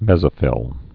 (mĕzə-fĭl, mĕs-)